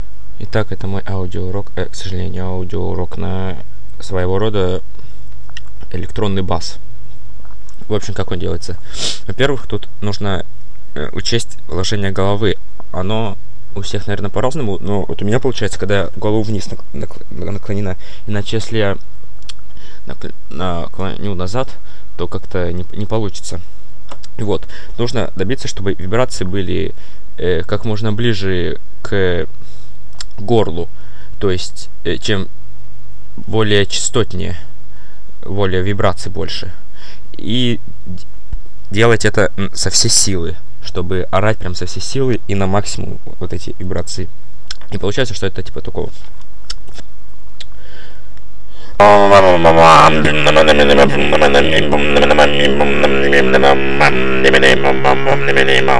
Мой аудио-урок на мощный электронный бас.